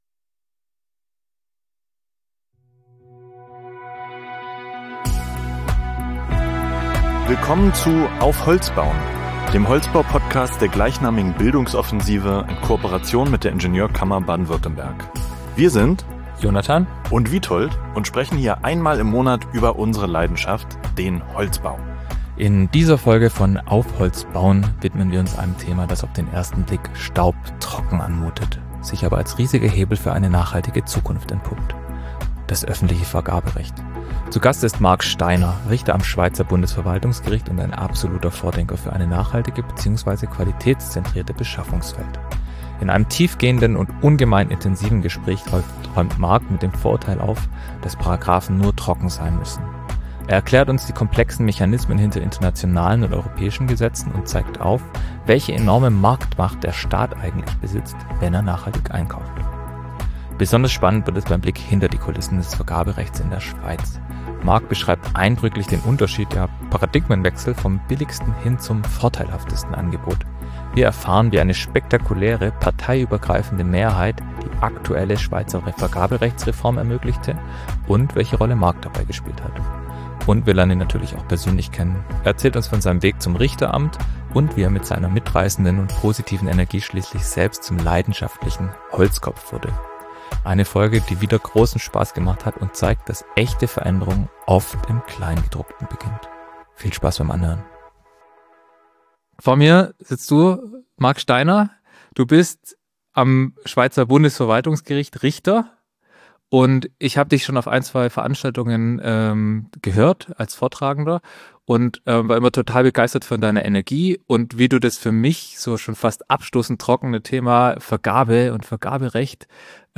Zu Gast ist Marc Steiner, Richter am Schweizer Bundesverwaltungsgericht und ein absoluter Vordenker für eine nachhaltige bzw. qualitätszentrierte Beschaffungswelt. In einem tiefgehenden und ungemein intensiven Gespräch räumt Marc mit dem Vorurteil auf, dass Paragrafen nur trocken sein müssen. Er erklärt uns die komplexen Mechanismen hinter internationalen und europäischen Gesetzen und zeigt auf, welche enorme Marktmacht der Staat eigentlich besitzt, wenn er nachhaltig einkauft.